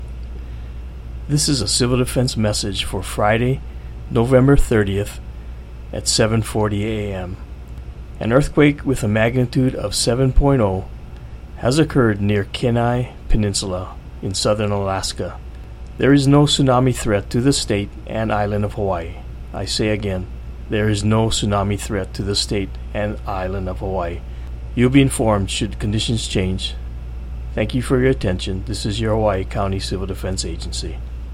Hawaii County Civil Defense audio message